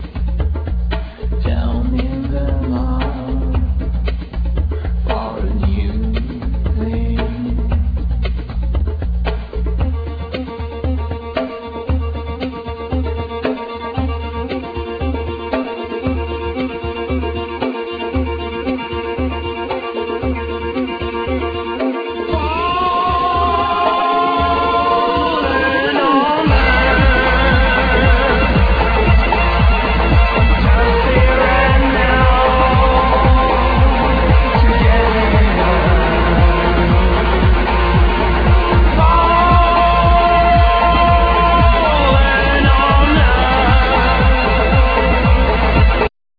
Drums,Djembe,Programming
Vocals,Keyboards,Programming
Violin
Djembe,Doundoun
Guitar